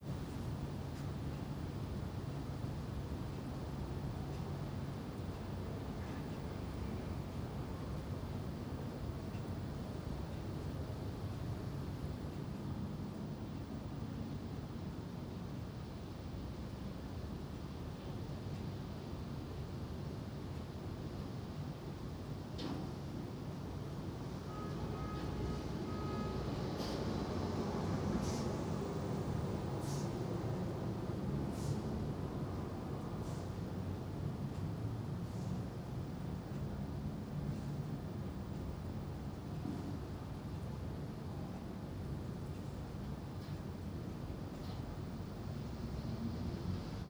Ambiente skyline de una ciudad desde la terraza de una oficina
Sonidos: Oficina
Sonidos: Ciudad